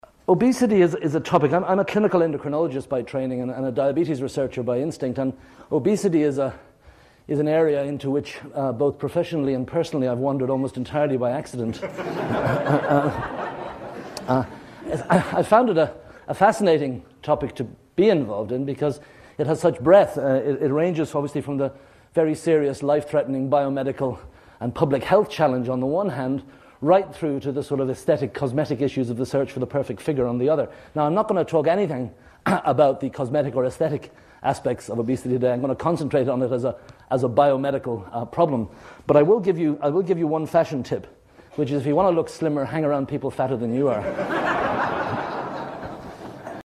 First Annual Public Lecture